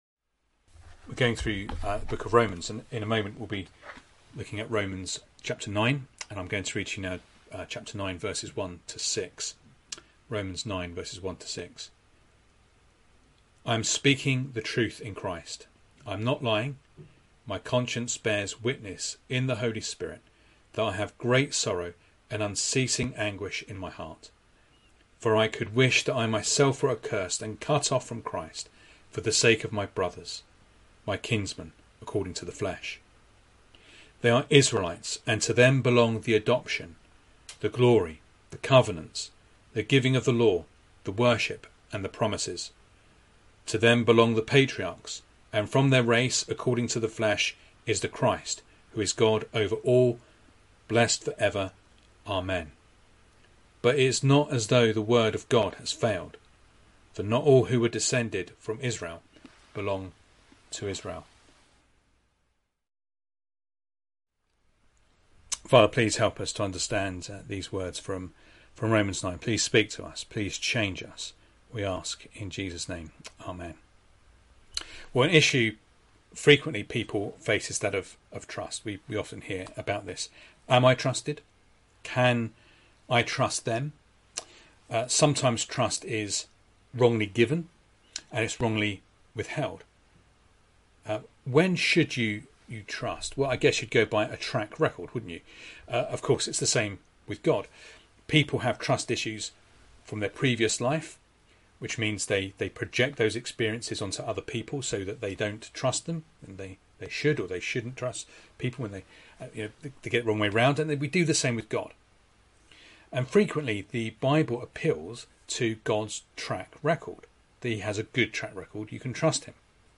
Sunday Evening Reading and Sermon Audio